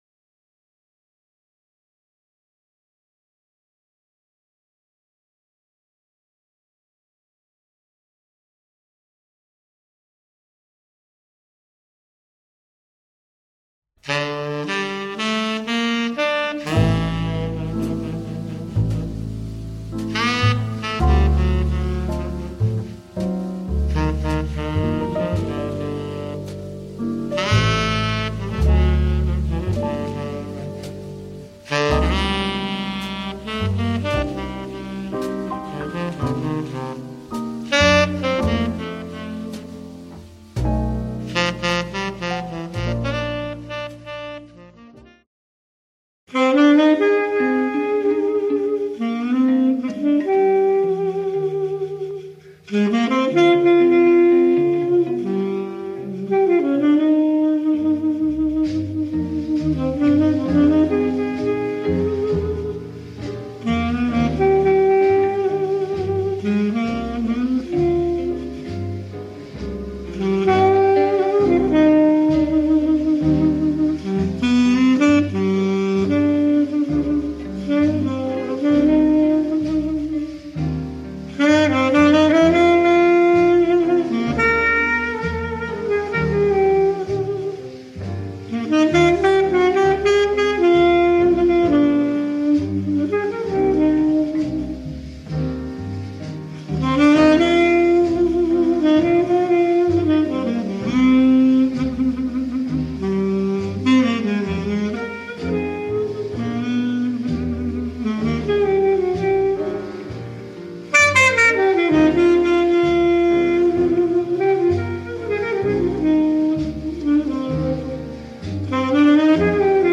Avui arribem al programa 25 i, com a bons hereus que som de Nova Orleans, ho celebrem amb tota aquesta tribu musical!! Hi jaurà jazz, blues, rhythm and blues, soul i gòspel.